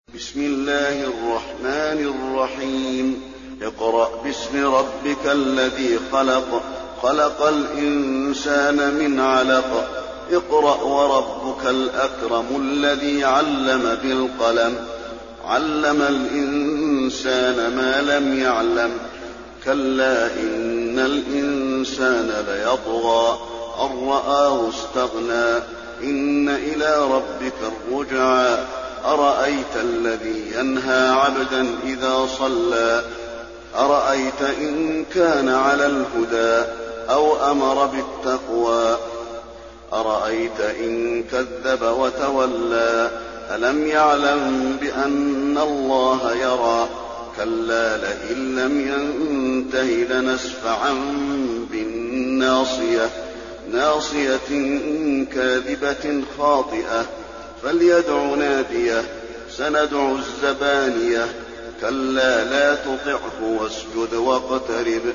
المكان: المسجد النبوي العلق